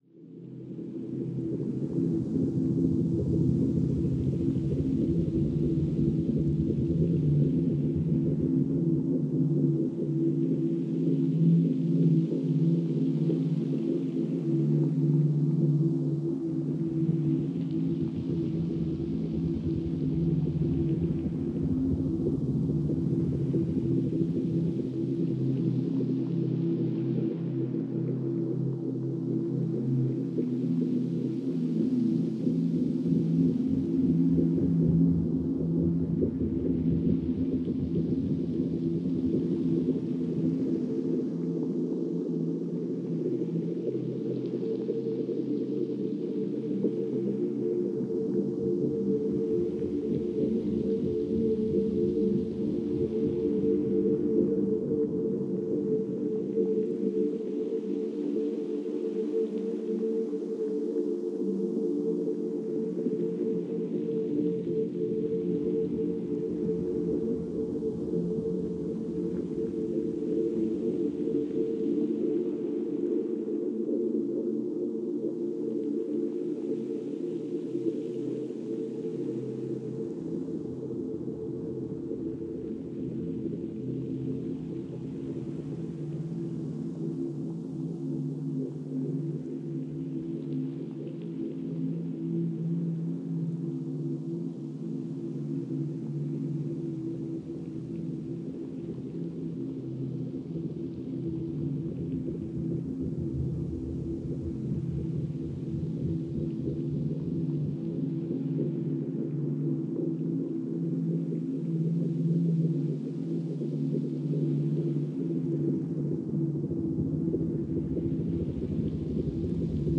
propose des sons apaisants